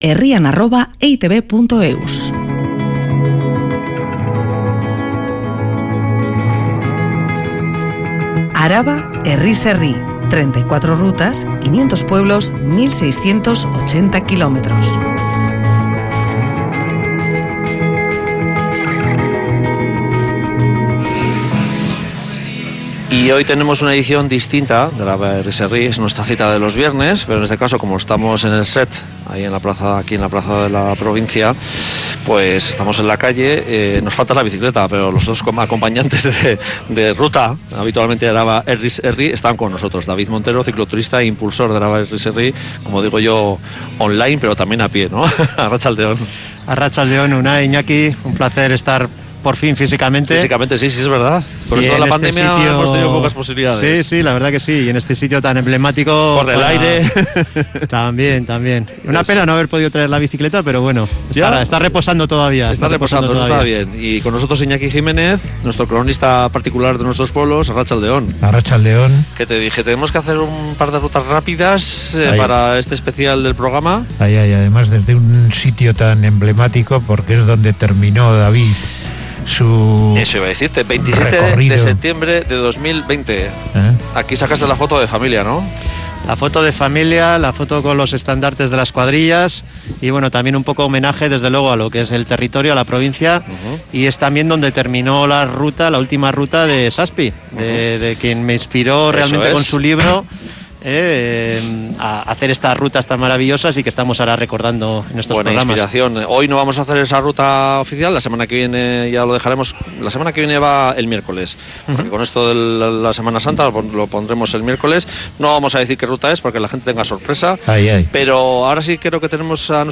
Programa especial desde este punto vital del territorio alavés